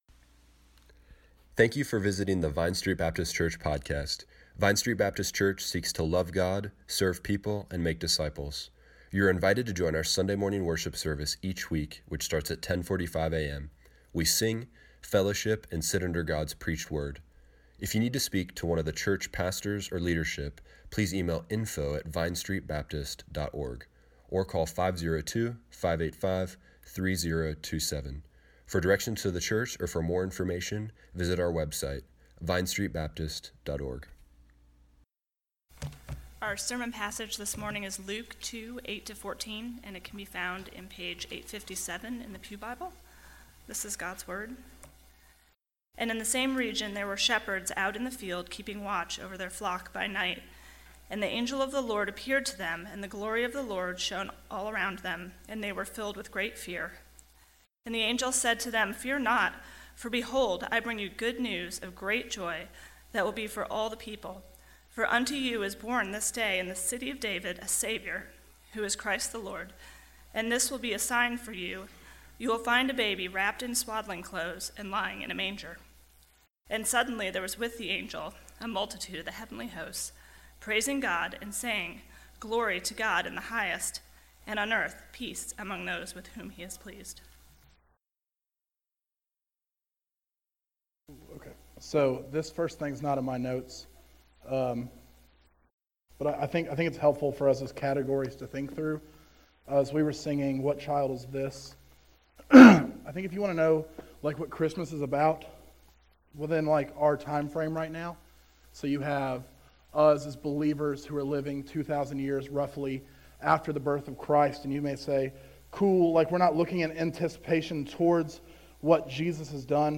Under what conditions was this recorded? Service Morning Worship